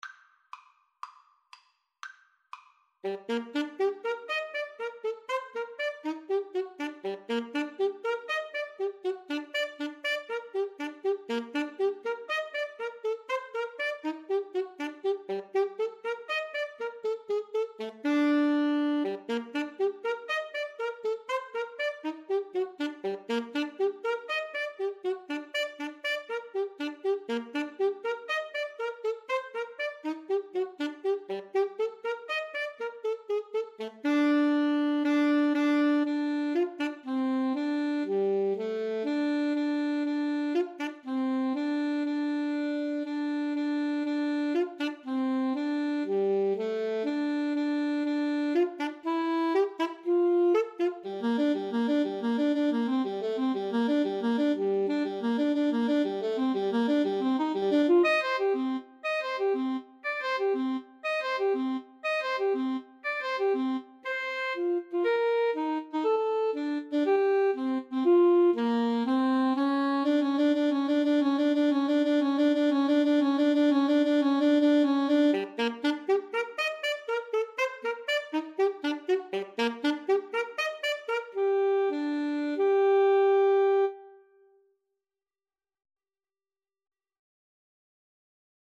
ClarinetAlto Saxophone
Allegro (View more music marked Allegro)